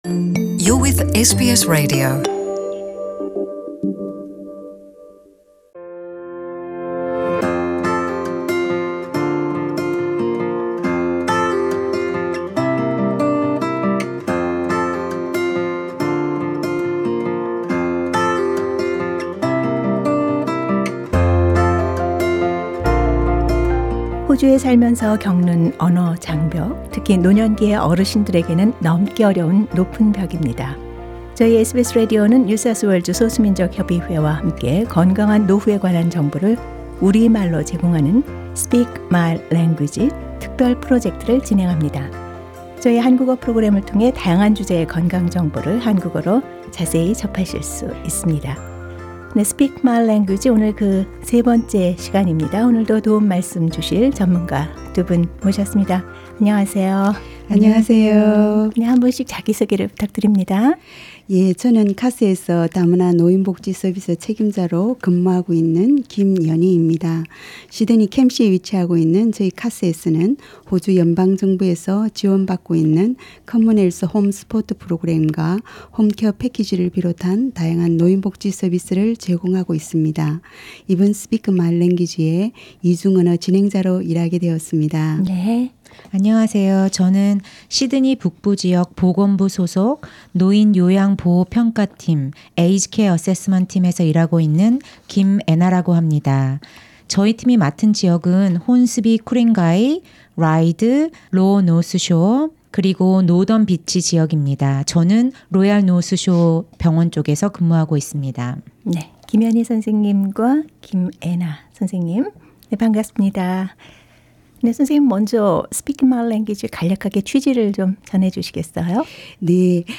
The topic demystifies what elder abuse is, describes the various kinds of elder abuse and different ways of identifying when elder abuse is present. Guest speakers will discuss how they have been able to address elder abuse in their own lives and health professionals will reflect on positive changes that can occur when there is intervention.